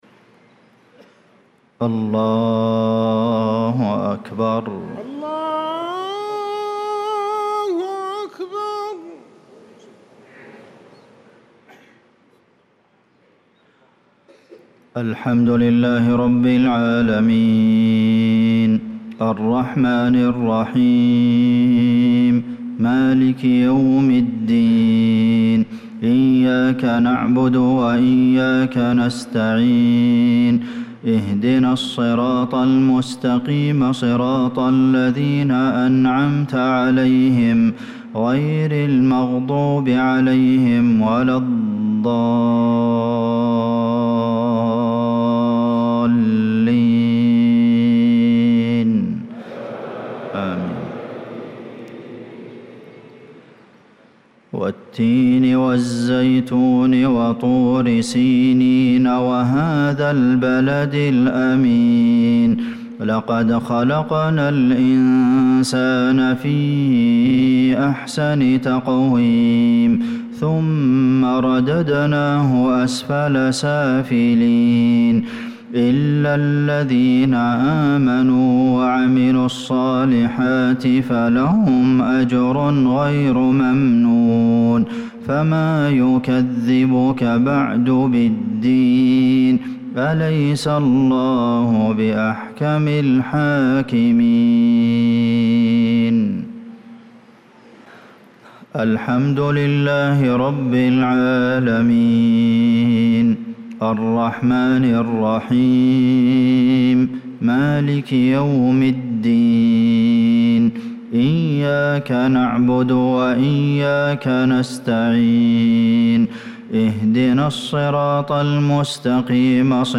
صلاة المغرب للقارئ عبدالمحسن القاسم 8 ربيع الآخر 1446 هـ
تِلَاوَات الْحَرَمَيْن .